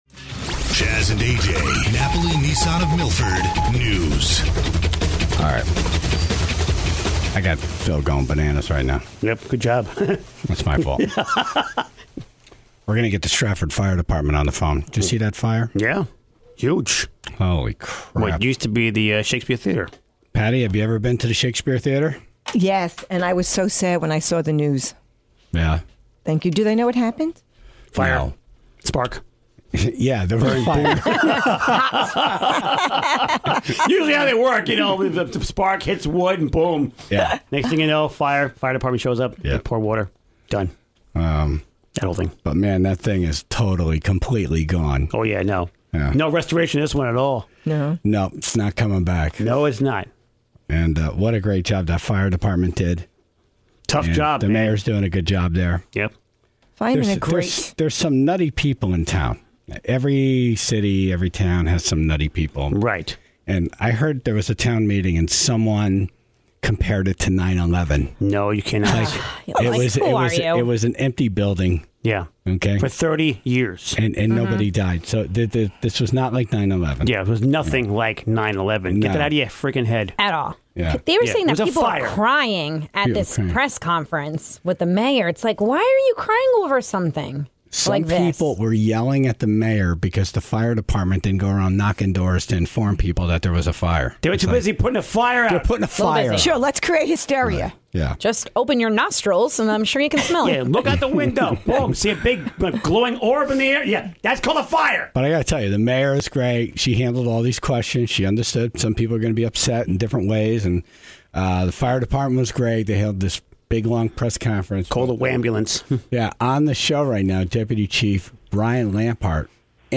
spoke to two of the Stratford Fire Fighters that were on scene to battle the blaze that decimated the historic Shakespeare Theater